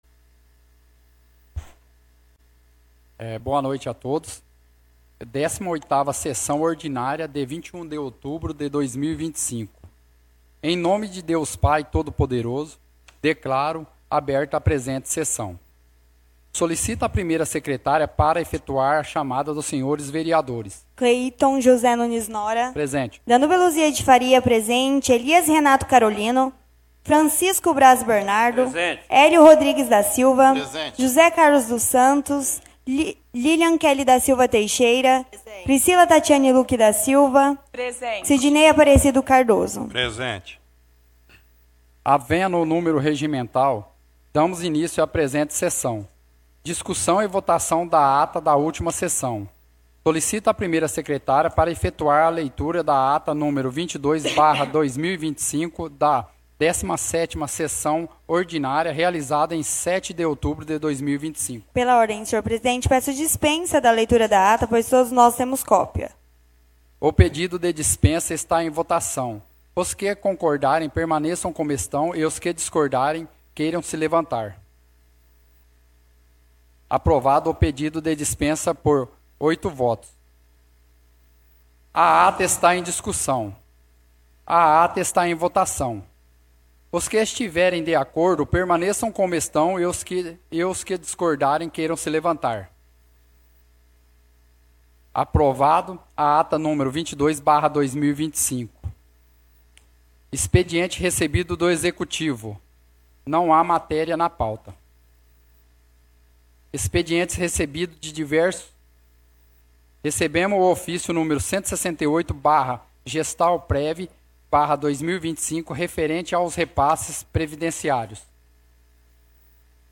Áudio da 18ª Sessão Ordinária – 21/10/2025